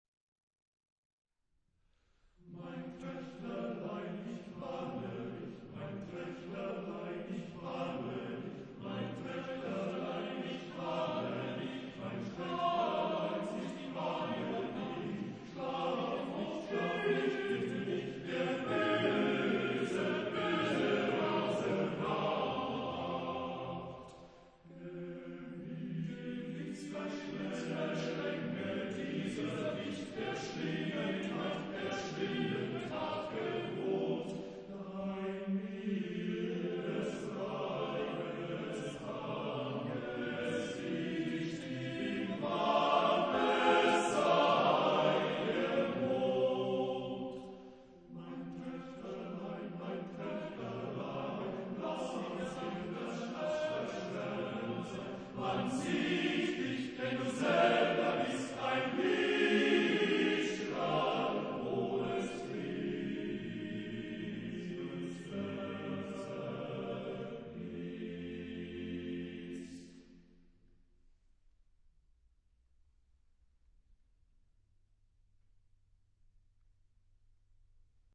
Genre-Stil-Form: weltlich ; Lied
Chorgattung: TTBB  (4 Männerchor Stimmen )